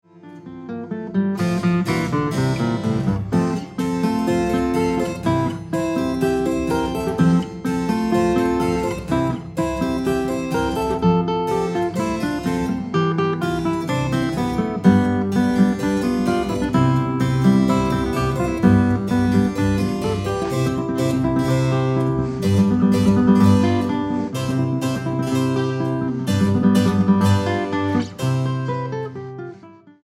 guitarra
clavecín
música original para guitarra y clavecín